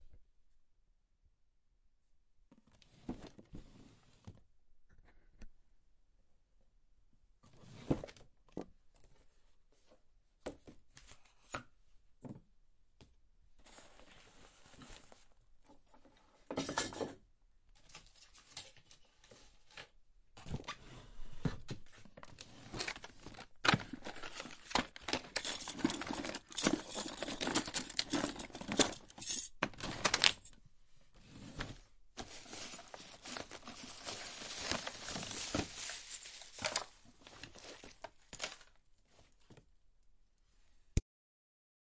Abandoned Factory metal post apocalyptic echoes » Going Through Drawer 2
描述：Recorded in an abandoned factory in Dublin.
标签： industrial bang clang factory metal crash noise
声道立体声